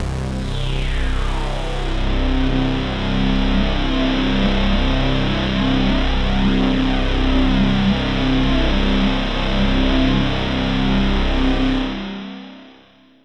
AMBIENT ATMOSPHERES-5 0003.wav